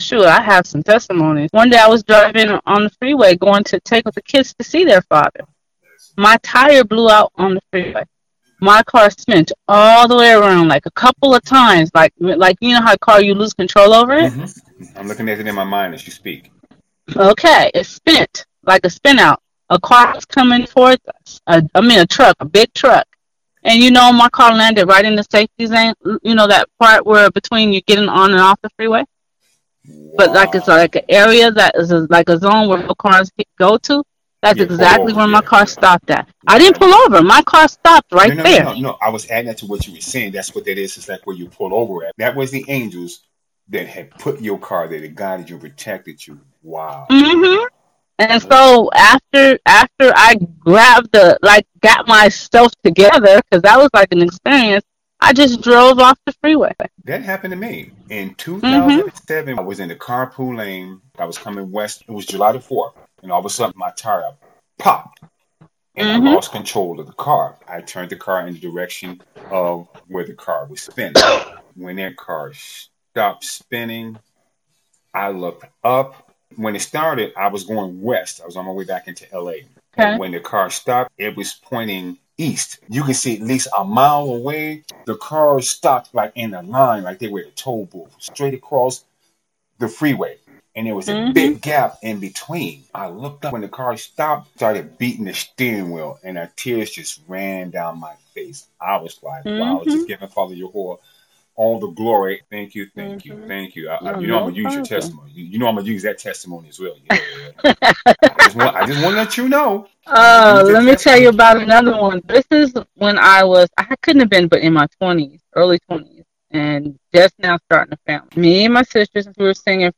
Wen yu call wee will racord it and wee will let "YU" tel yor testamo'nee.